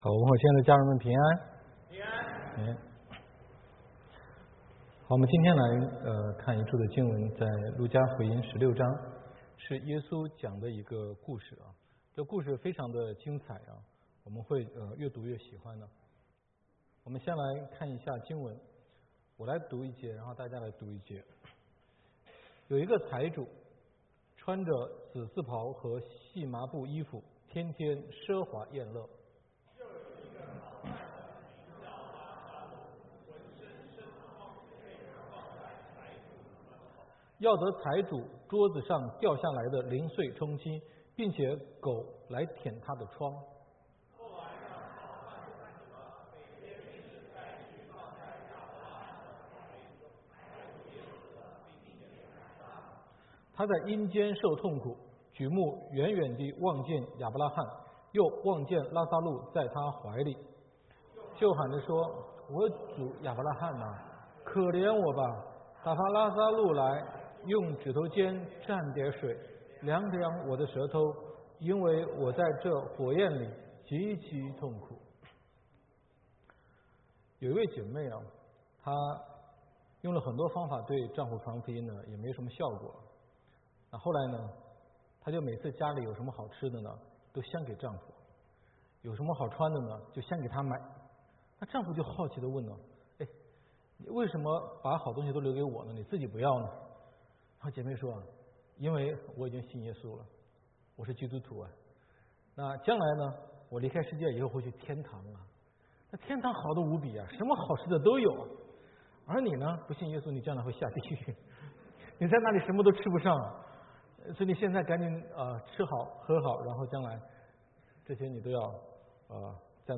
基督之家第五家HOC5 講道